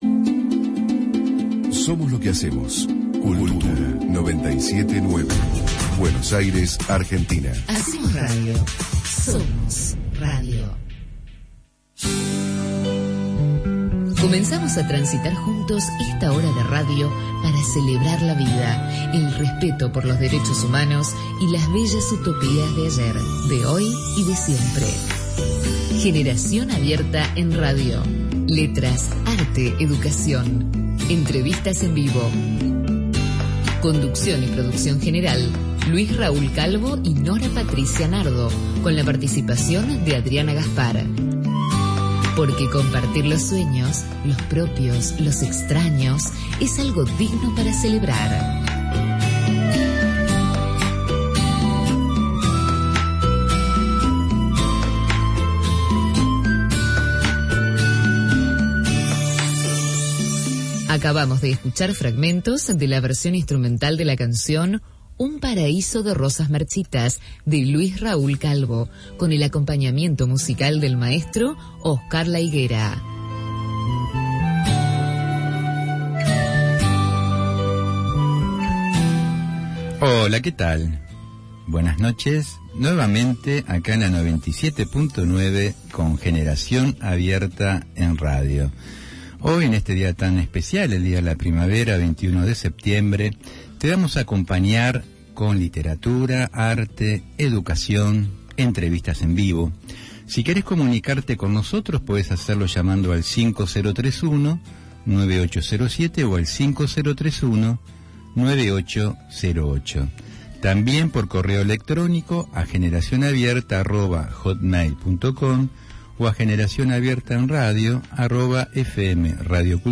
Por la Radio AM 1010 “Onda Latina” , Buenos Aires, Argentina.